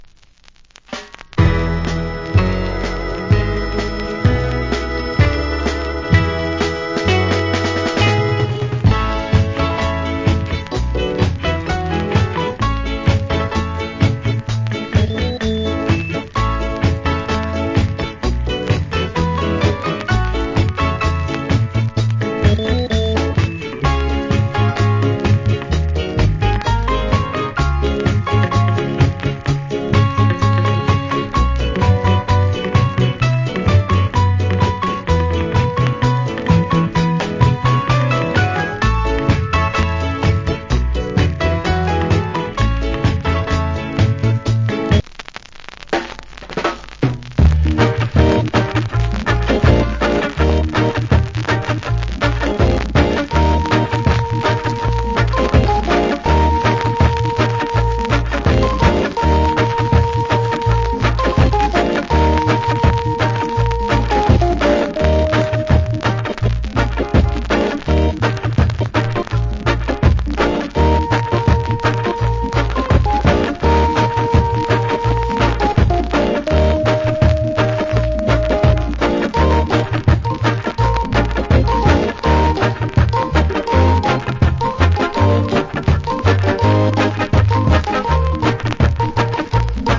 Wicked Reggae Inst.